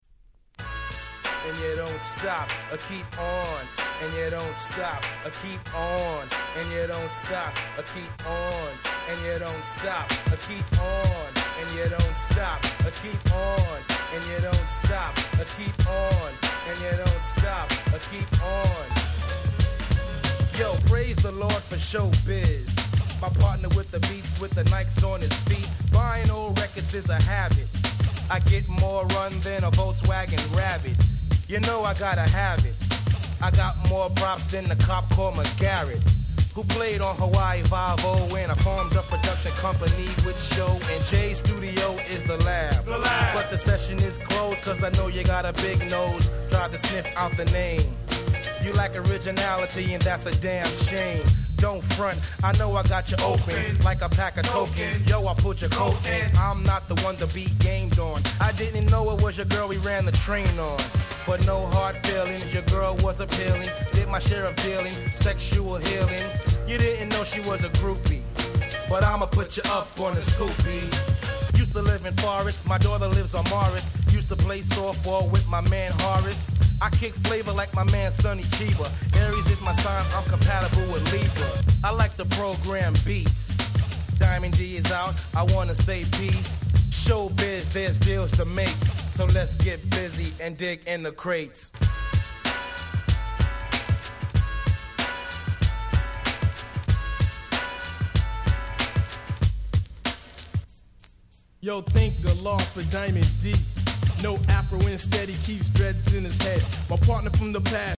Boom Bap ブーンバップ